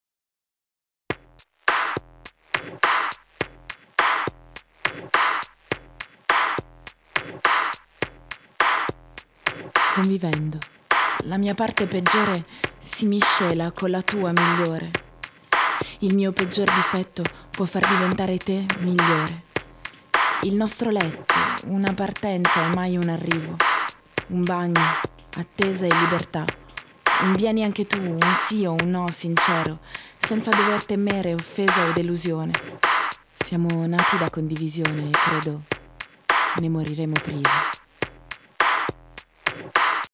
Genre: Pop / Italia